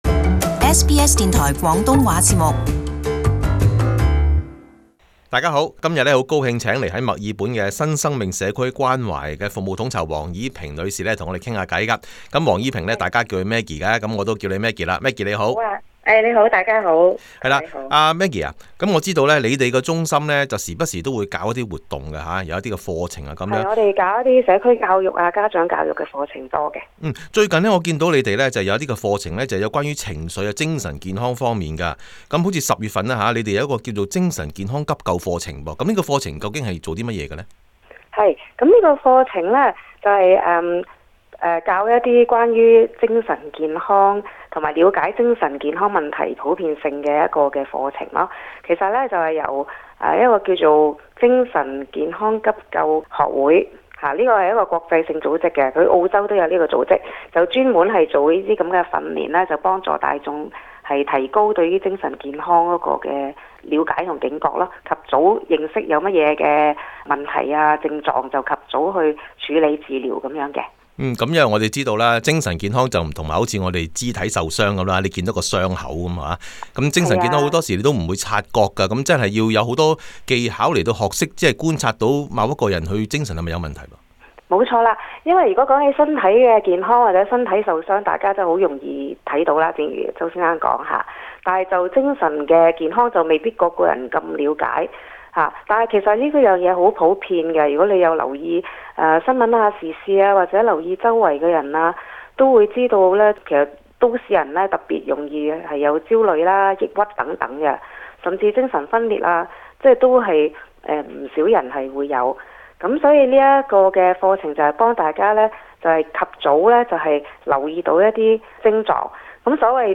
【社團專訪】新生命社區關懷精神健康課程